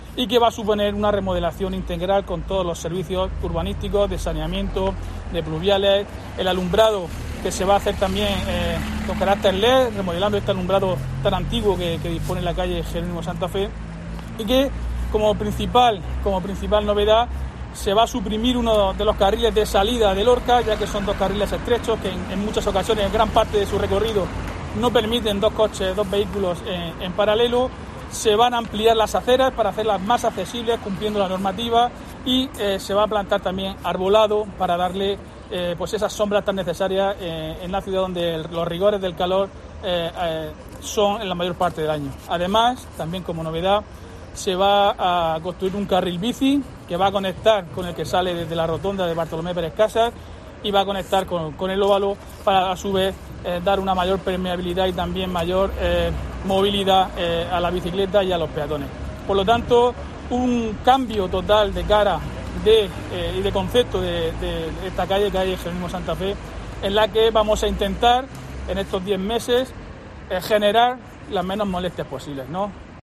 Diego José Mateos, alcalde de Lorca sobre obras en Santa Fé